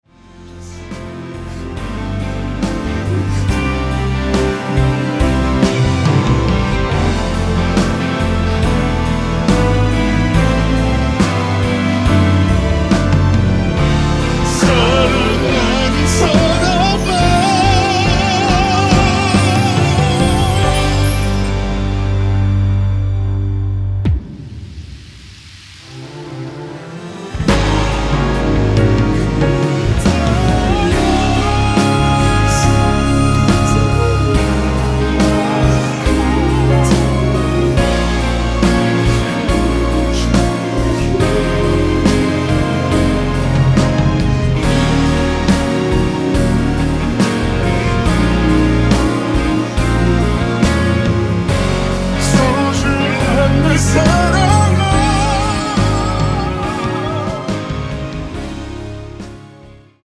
원키에서(-3)내린 코러스 포함된 MR입니다.
앞부분30초, 뒷부분30초씩 편집해서 올려 드리고 있습니다.
중간에 음이 끈어지고 다시 나오는 이유는